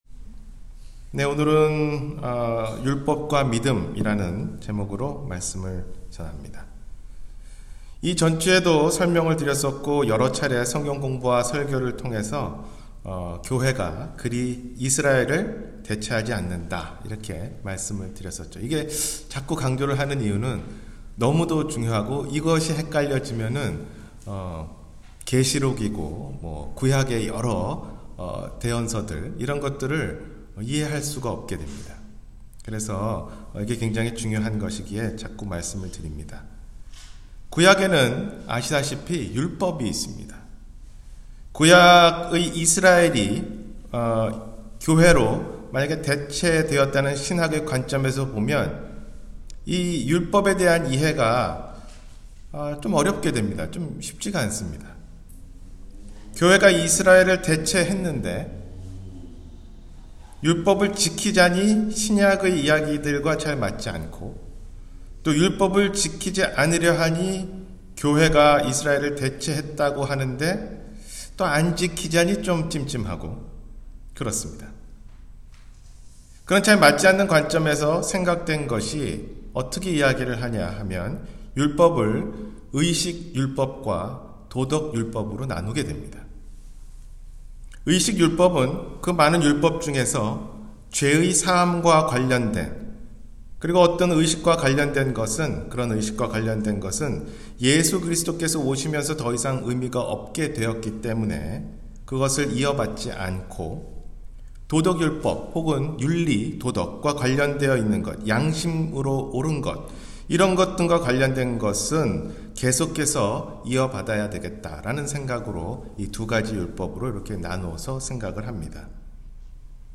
율법과 믿음 – 주일설교